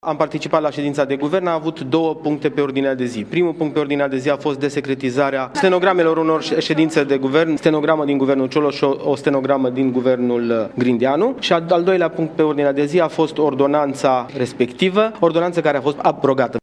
Iată declarațiile ministrului Sănătății, Florian Bodog, despre ședința de Guvern de astăzi: